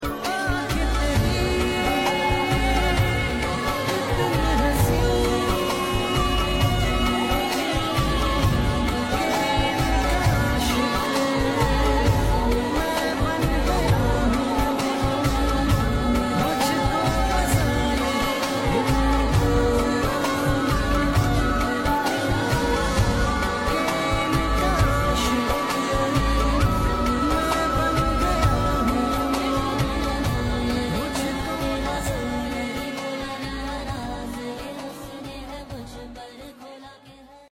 Mitti express crossing Vehari //